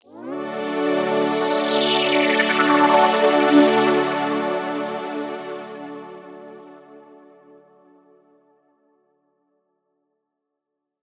Chords_Dmaj_01.wav